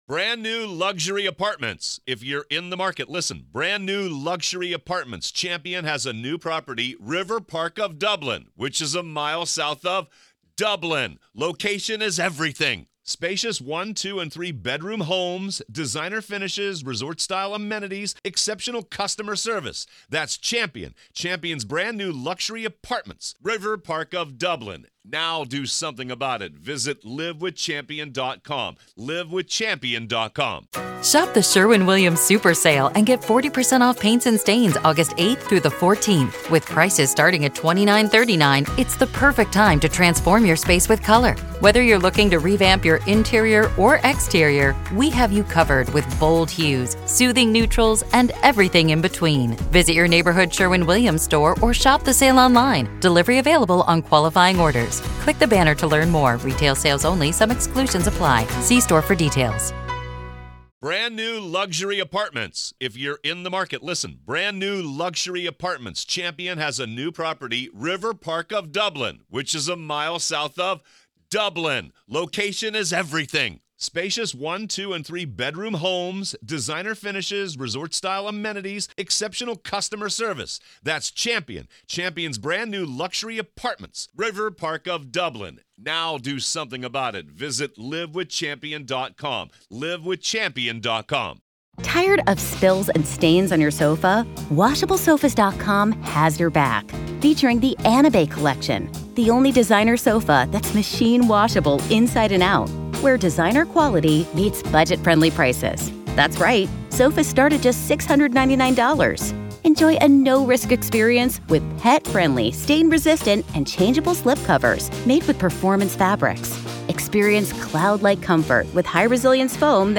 For the first time, you’ll see it—and hear directly from the man behind the camera.